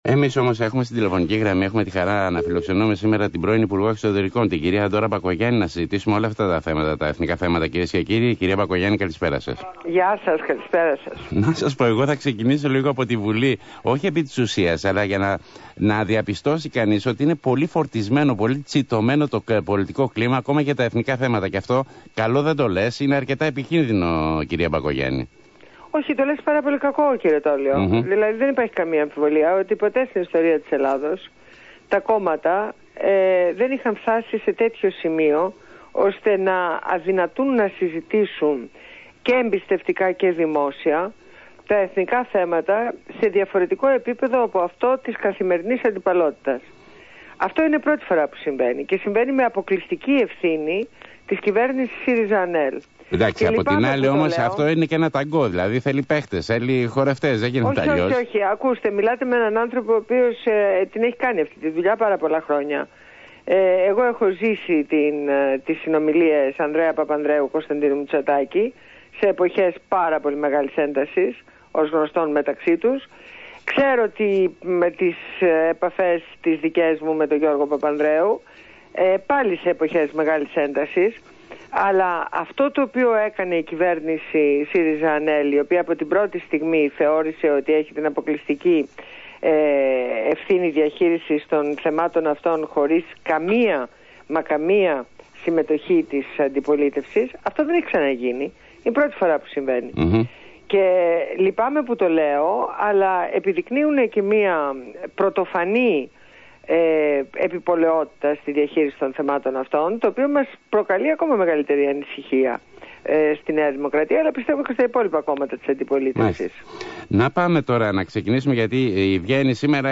Συνέντευξη στο ραδιόφωνο Αθήνα 9.84